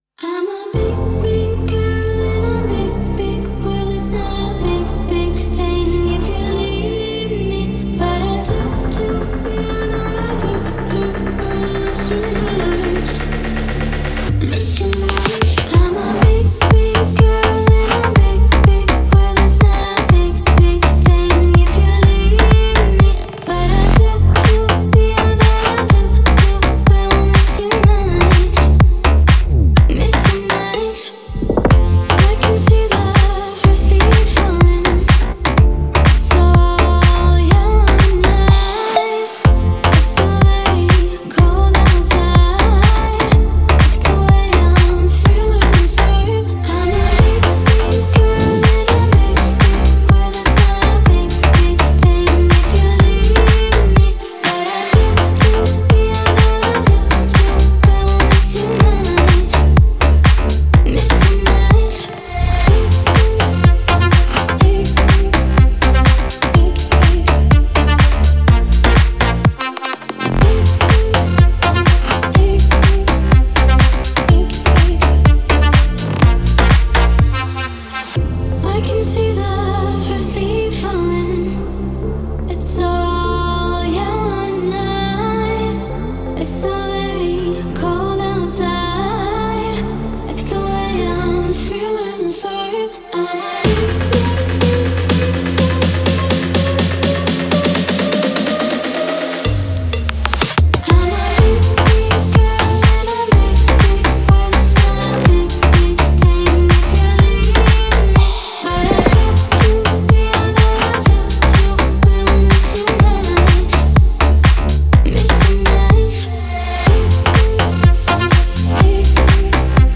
это трогательная и мелодичная песня в жанре поп